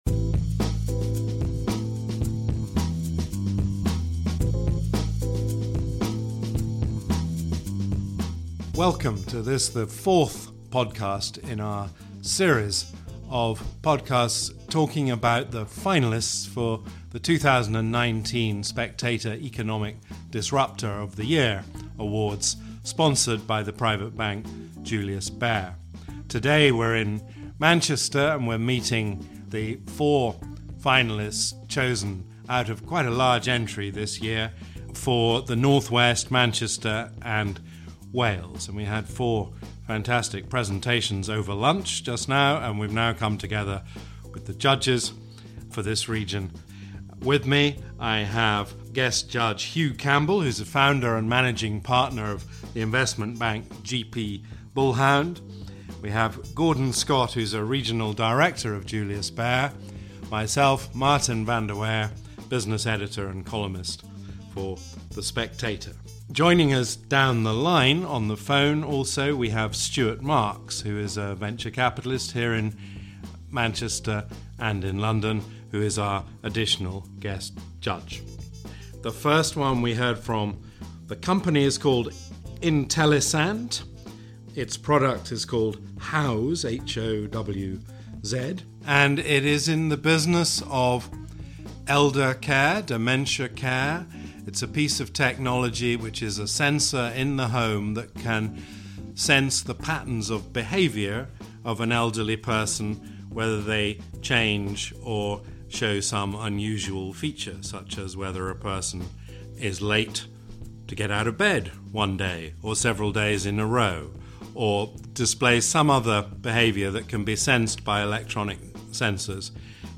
News Commentary, News, Daily News, Society & Culture